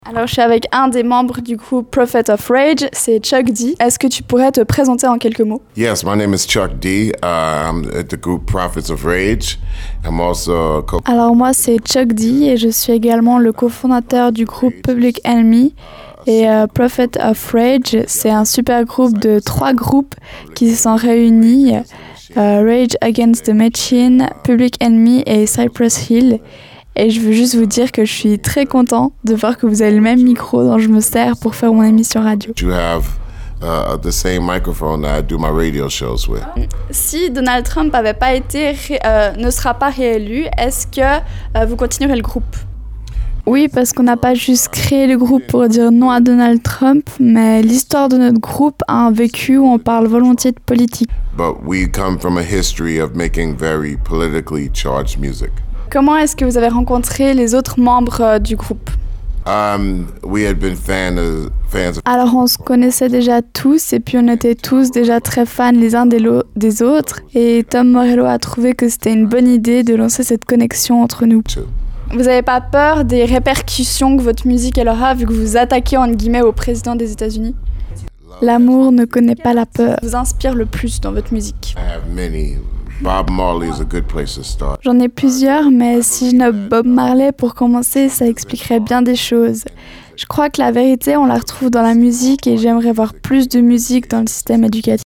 Interview – Chuck D – Prophets Of Rage